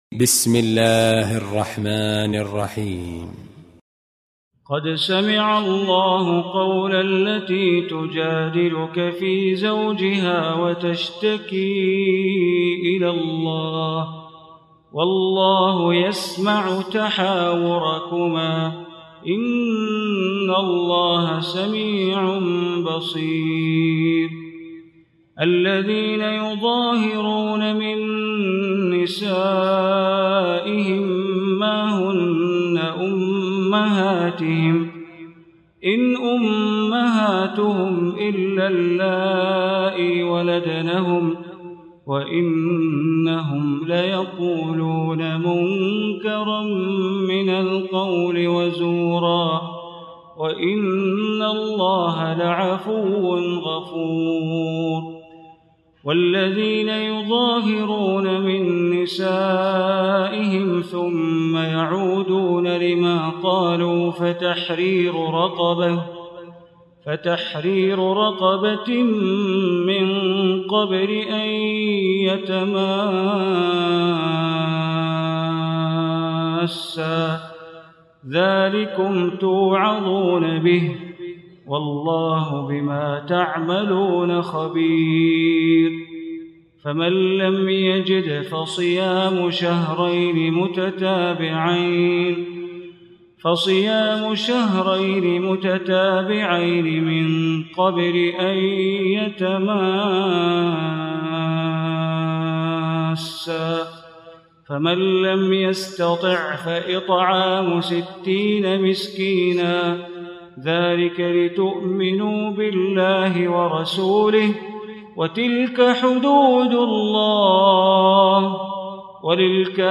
Surah Mujadila Recitation by Sheikh Bandar Baleela
Surah Mujadila, listen online mp3 tilawat / recitation in Arabic recited by Imam e Kaaba Sheikh Bandar Baleela.